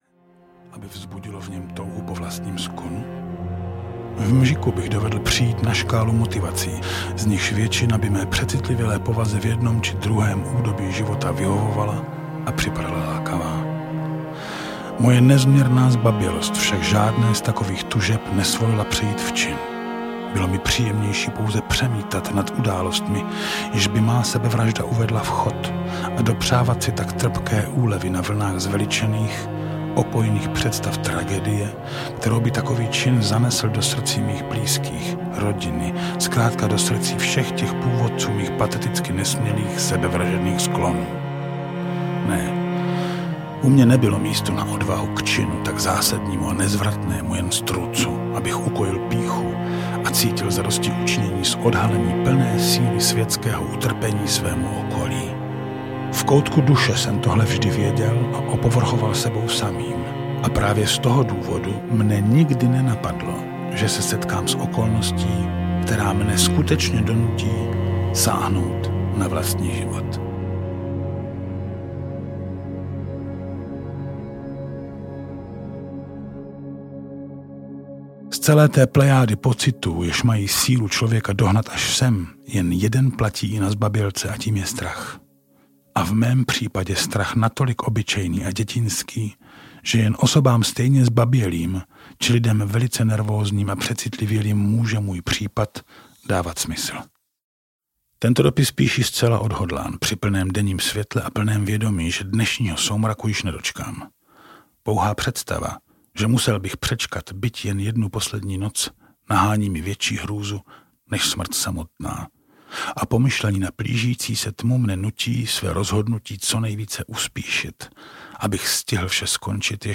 Opravdový zbabělec audiokniha
Ukázka z knihy
opravdovy-zbabelec-audiokniha